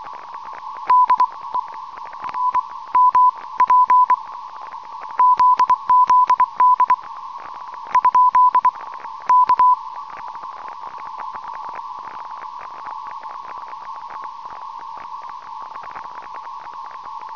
Modtager et cw signal på 5167 Khz
Det er militært eller andet kommercielt.